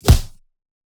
GDYN_Punching_Perc_PRO_SH - 1.wav